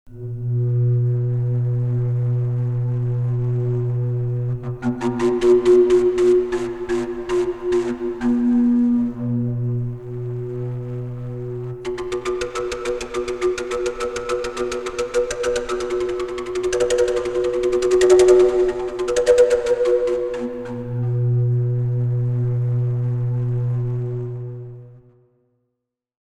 Fujara